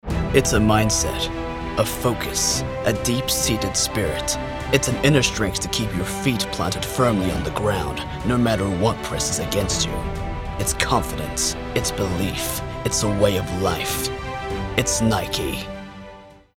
standard us | natural